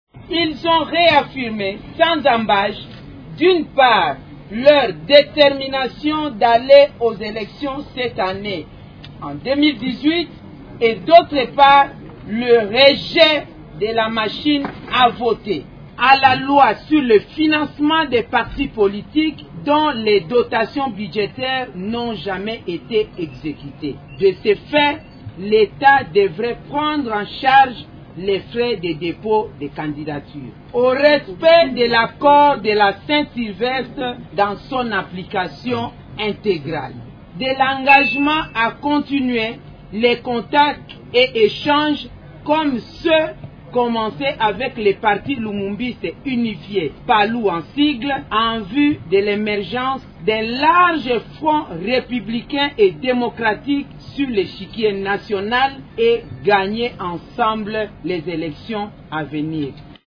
Ces opposants « ont réaffirmé leur détermination d’aller aux élections cette année 2018, d’une part, et d’autre part le rejet l’usage de la machine à voter » lors de ces élections, a indiqué Mme Bazaiba, lisant la déclaration commune.
Extrait de cette déclaration commune: